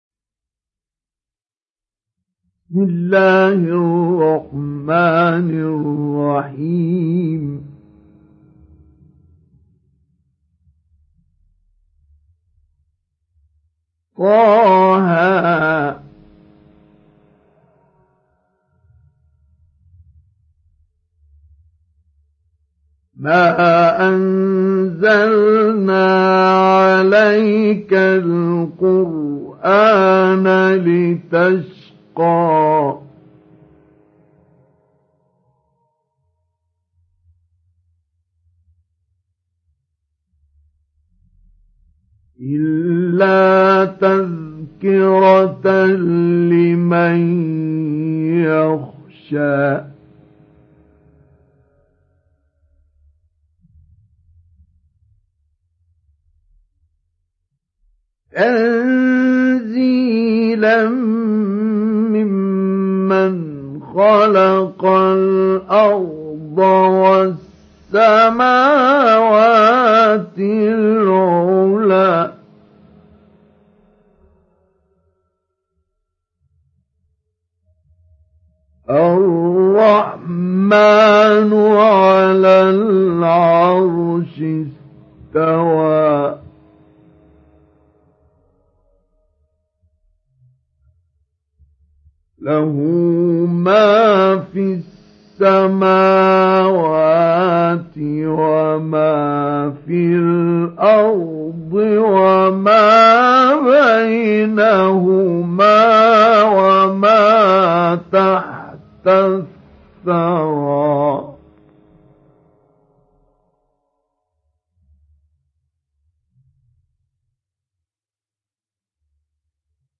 Surah Taha Download mp3 Mustafa Ismail Mujawwad Riwayat Hafs from Asim, Download Quran and listen mp3 full direct links